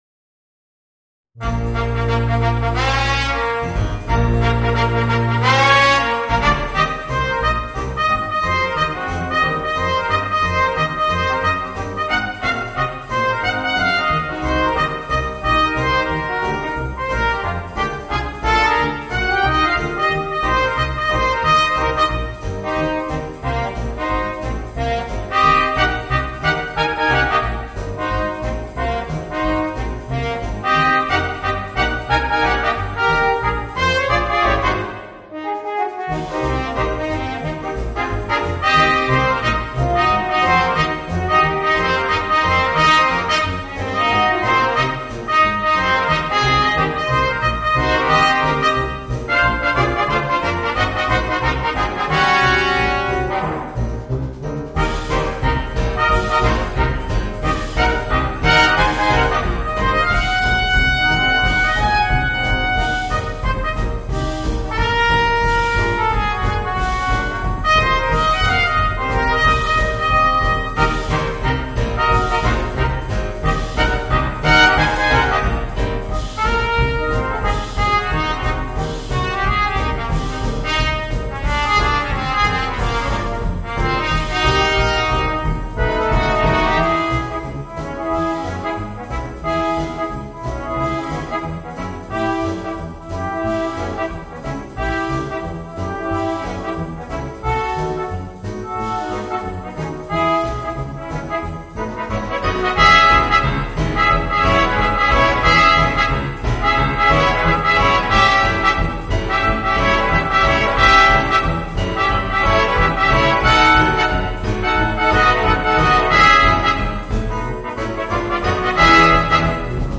Voicing: 2 Trumpets, 2 Trombones and Drums